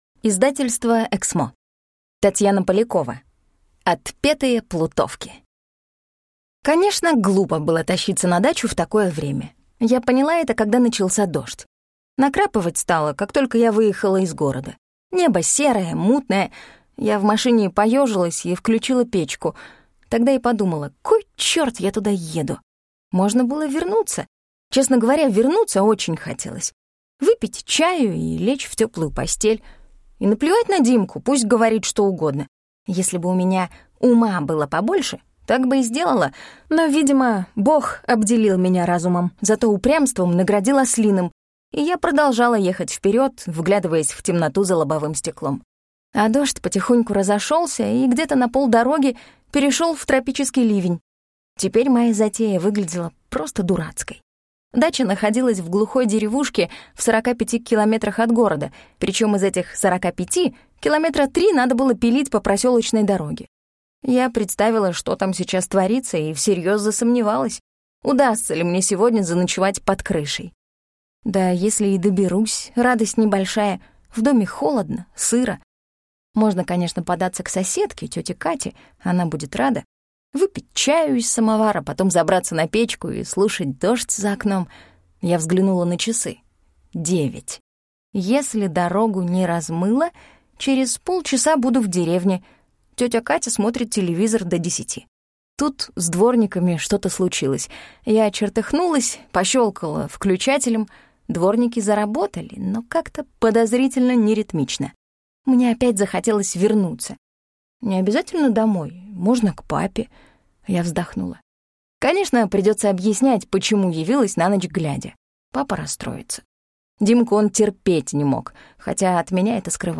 Аудиокнига Отпетые плутовки | Библиотека аудиокниг